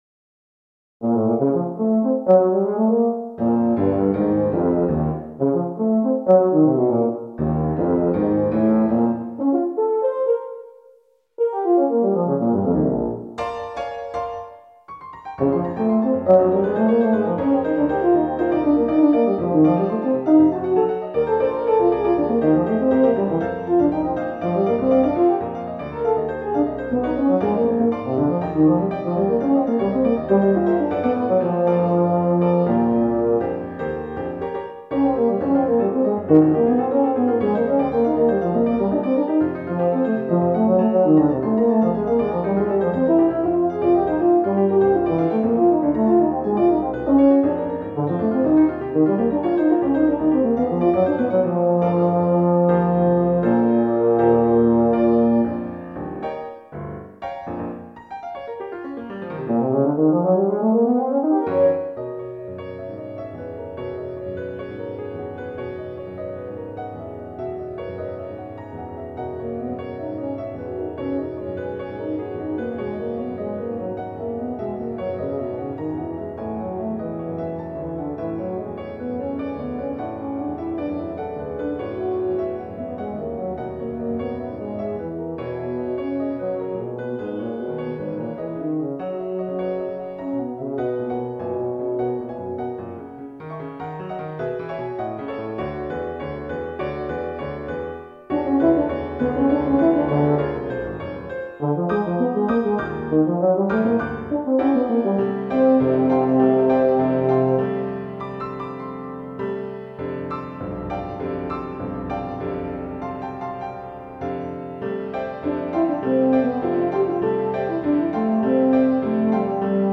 Voicing: Euphonium Solo